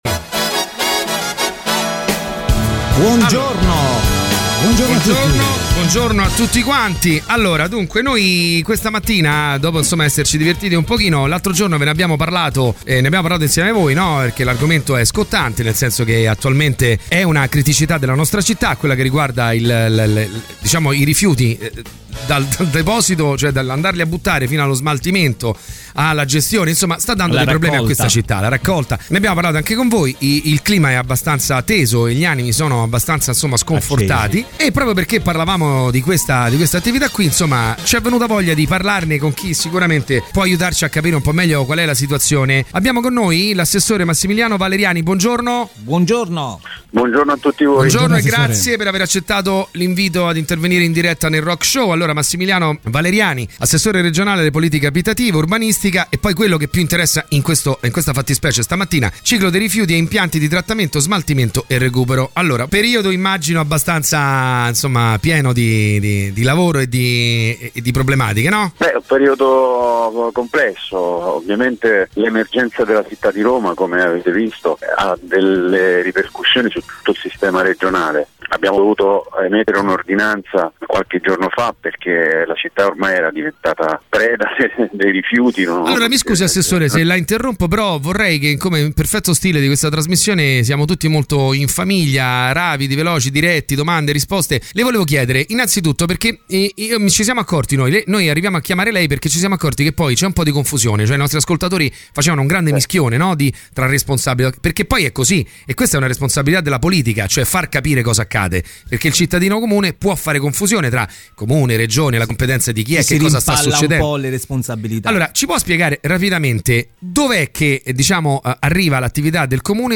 Intervista: Massimiliano Valeriani, Regione Lazio (11-07-19)